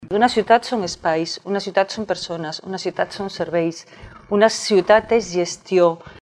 Debat Electoral la Bisbal 2019
Com no podia ser d’altra manera Ràdio Capital ha emès el col·loqui.